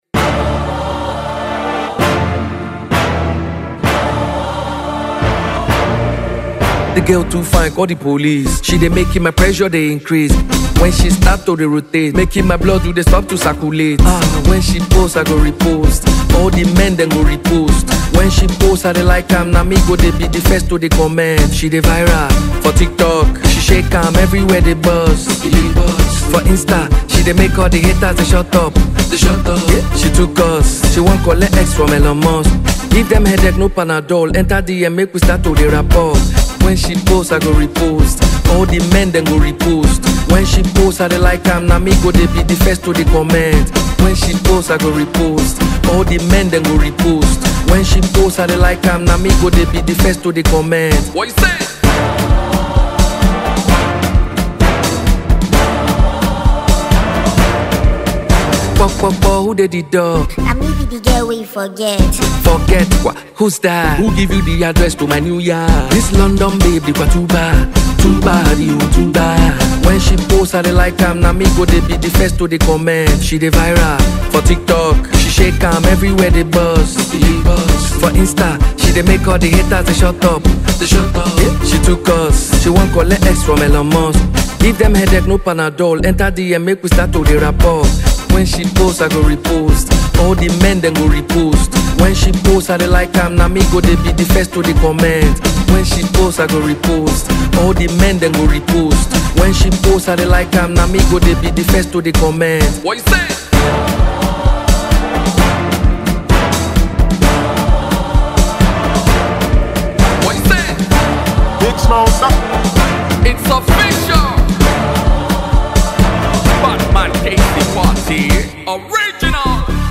addictive vibe, energetic rhythm